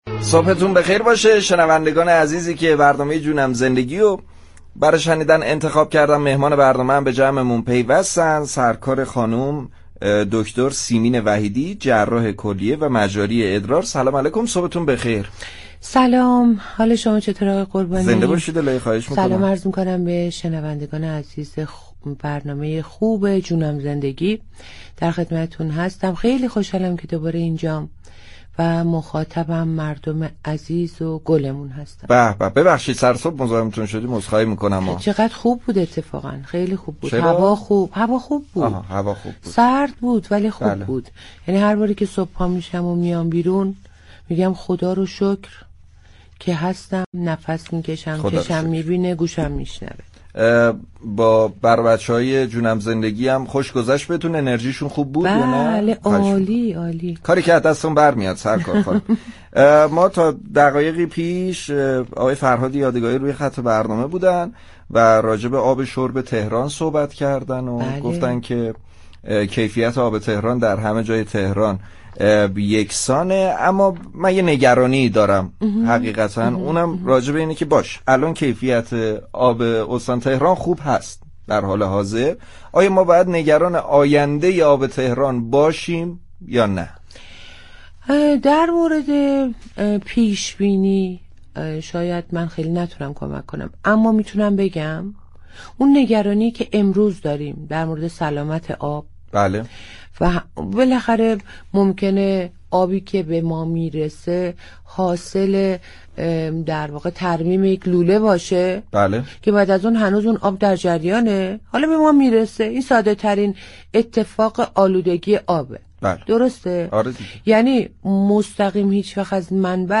یك جراح كلیه و مجاری ادراری به رادیو تهران گفت: فیلترهای كلیه انسان بسیار ظریف و میكروسكوپی هستند كه در طول زمان می‌توانند مسدود شوند. استفاده از میوه ها ، پروتئین های گیاهی و نوشیدن آب كافی به سلامت كلیه‌ها كمك می‌كند.